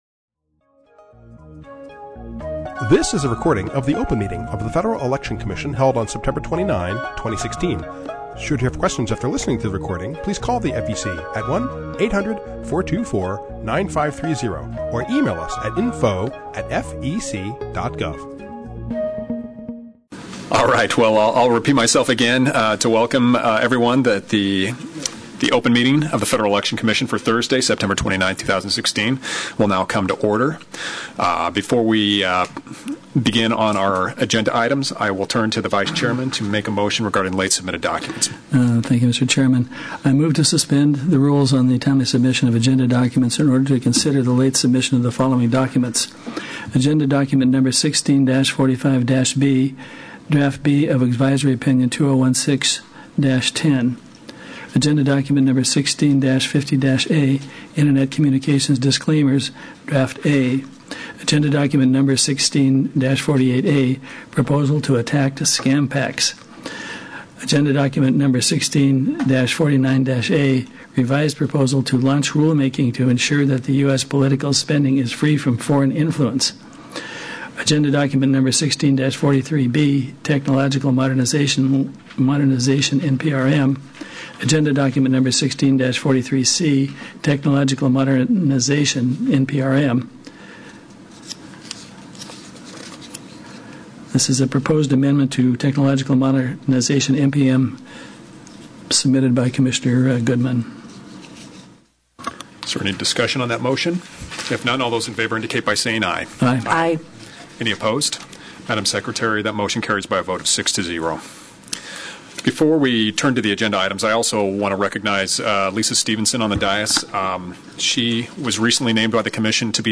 The vote was on a motion by Commissioner Lee Goodman to add books and films under the categories of press protected from regulation under the FEC's Press Exemption. Listen to the debate here, at minutes 8:53 - 22:30.